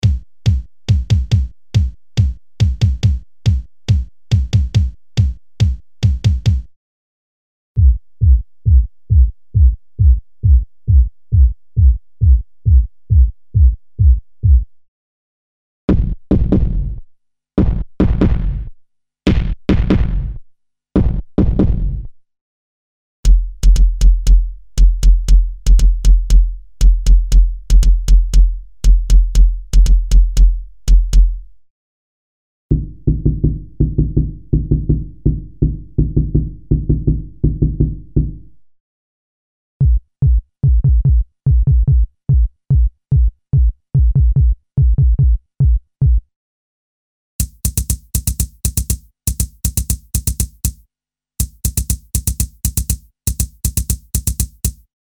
Acoustic and electronic single drum programs for various music styles.
Info: All original K:Works sound programs use internal Kurzweil K2500 ROM samples exclusively, there are no external samples used.